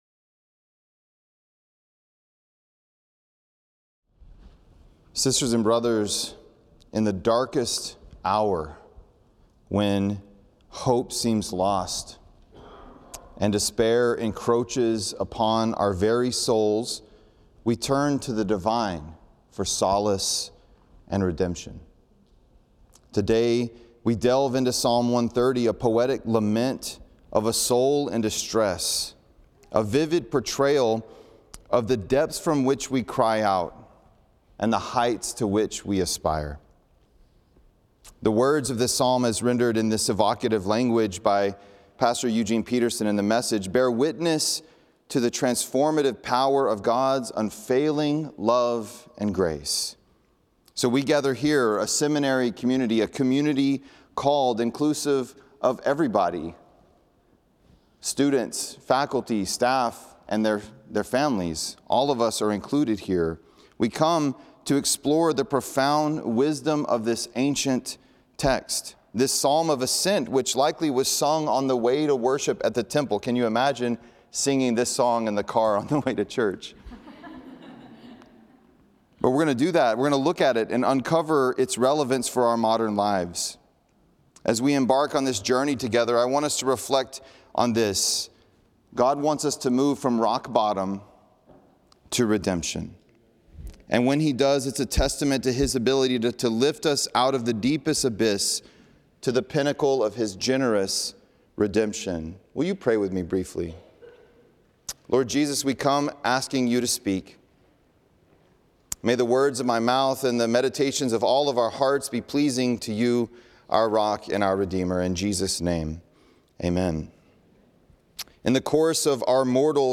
The following service took place on Tuesday, March 21, 2023.